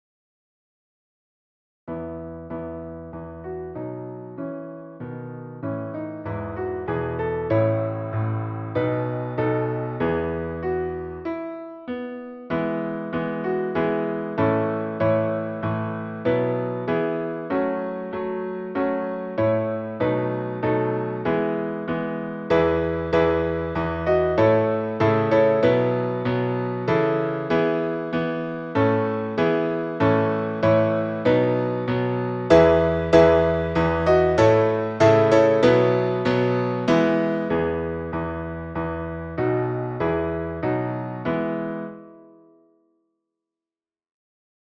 국가 연주 녹음